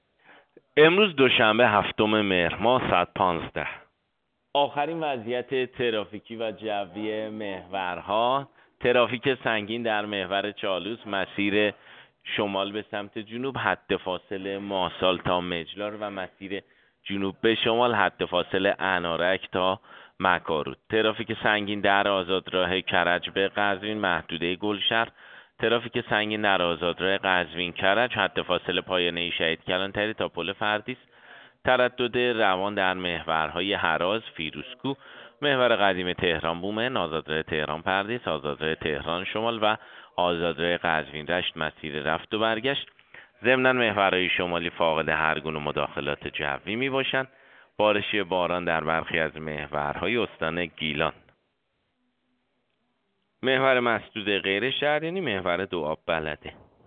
گزارش رادیو اینترنتی از آخرین وضعیت ترافیکی جاده‌ها ساعت ۱۵ هفتم مهر؛